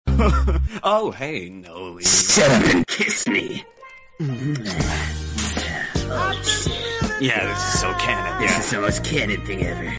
From the official voice actors, THIS is canon.